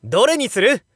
日本語 English 前 【ボイス素材】ゲーム用ナレーション
Narration-choice_05.wav